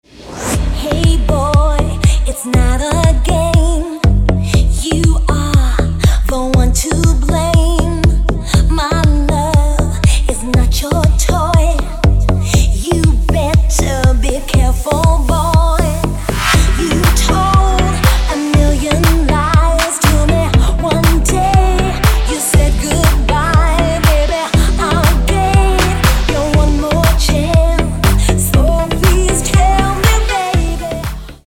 • Качество: 256, Stereo
женский вокал
deep house
dance
club